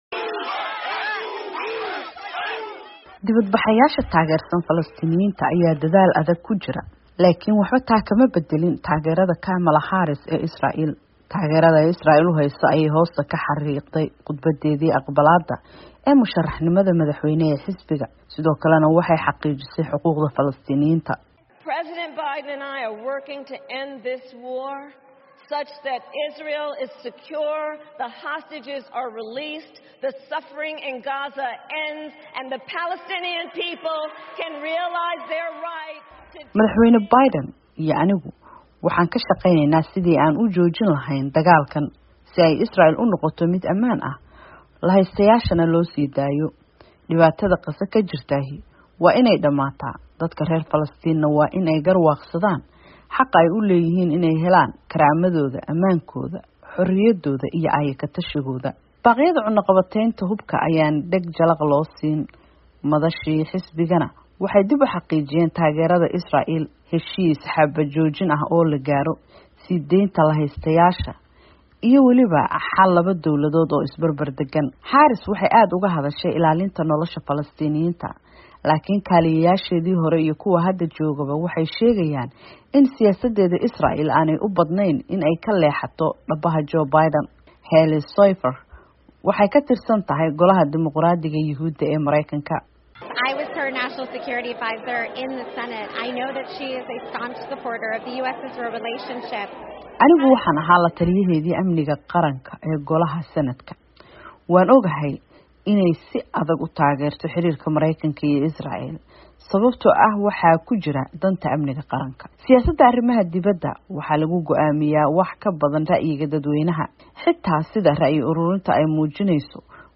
Harris contrasts her vision with Trump’s as she accepts Democrats’ nomination